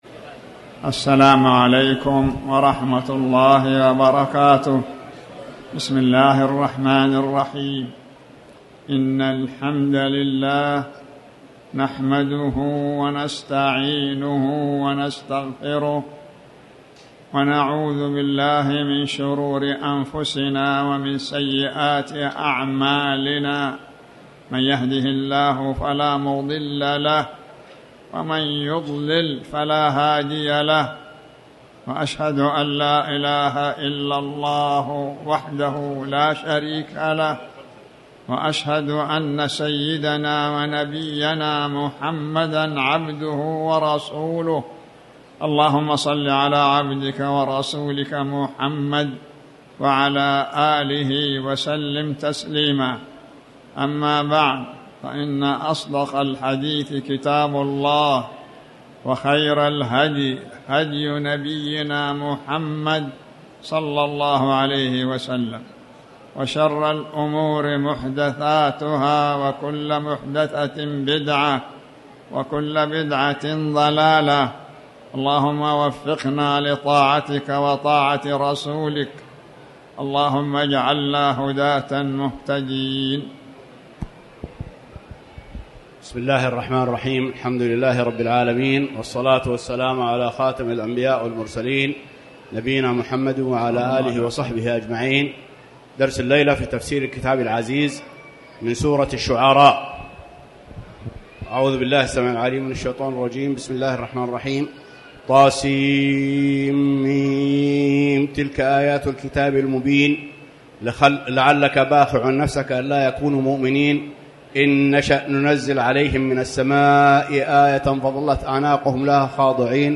تاريخ النشر ٧ ذو القعدة ١٤٤٠ هـ المكان: المسجد الحرام الشيخ